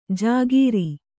♪ jāgīri